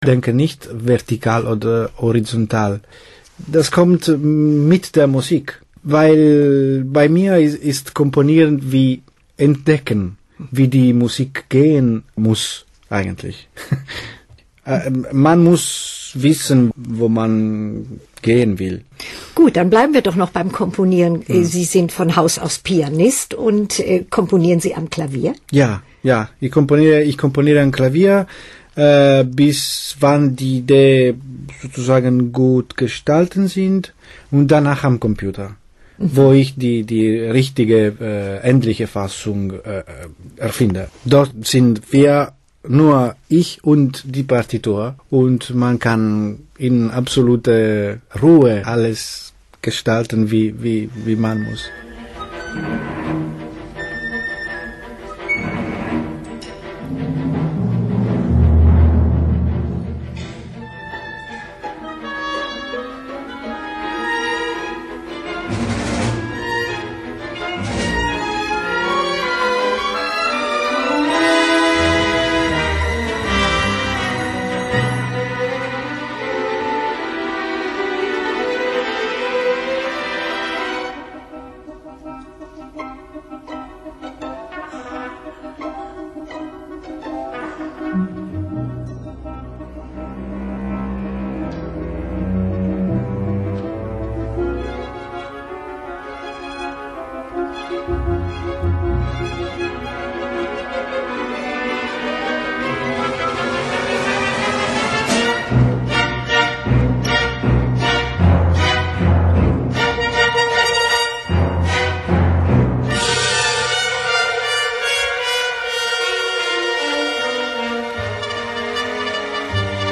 Ritratto radiofonico con intervista ed esempi musicali (MDR Figaro, Halle)